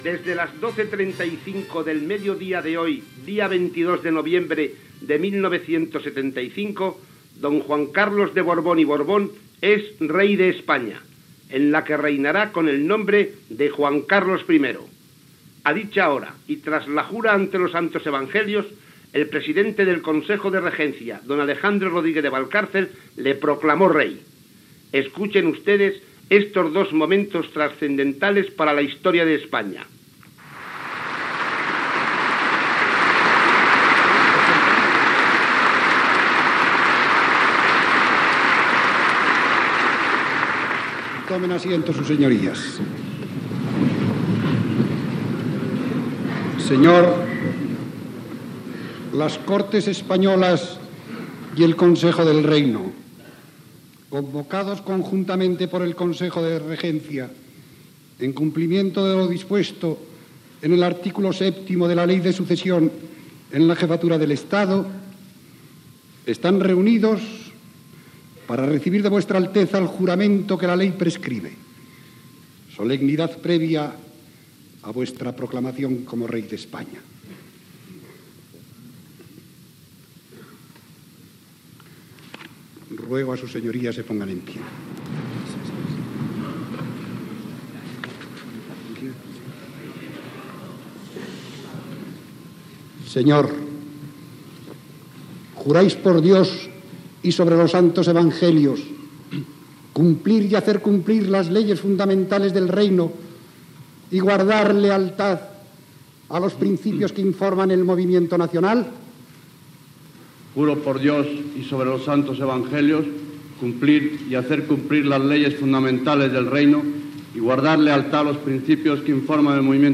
Discurs de presa de possessió com a rei de Juan Carlos I, amb elogi a la figura del general Francisco Franco i objectius de la nova etapa monàrquica.
Informatiu